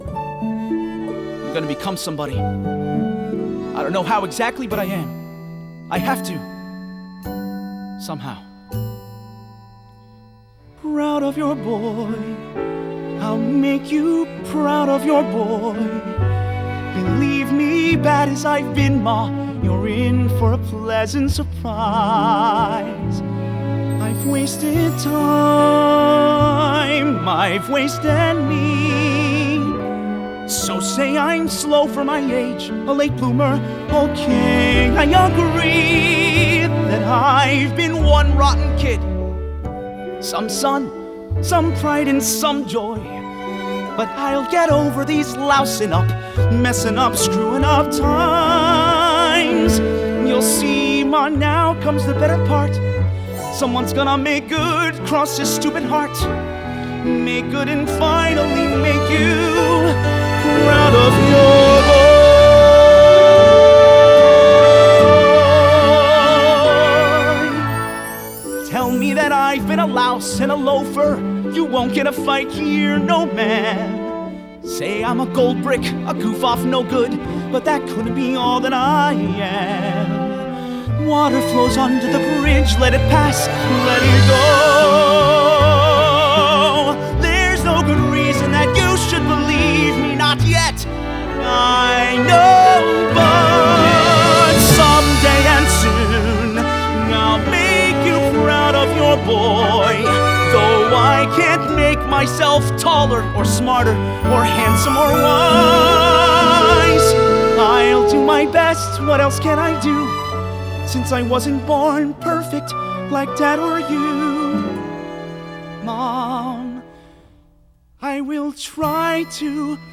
1992   Genre: Soundtrack   Artist